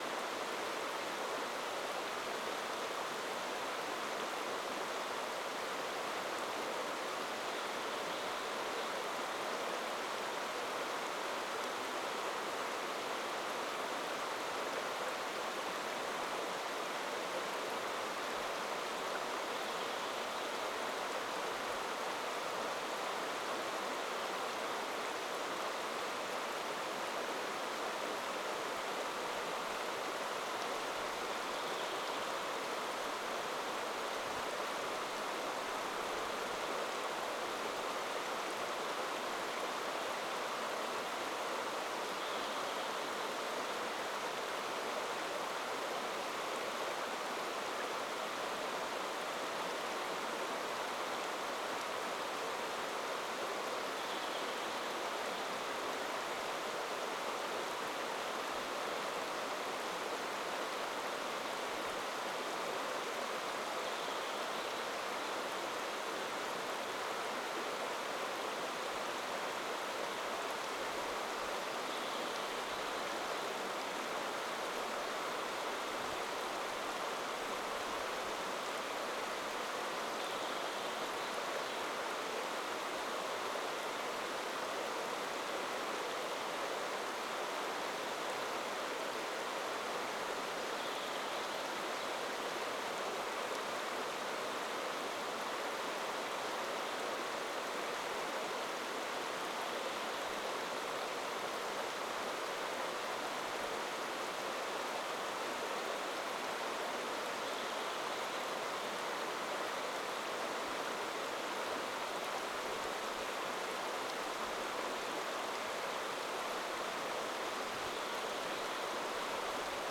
Quellrauschen mir Reverb loopbar.wav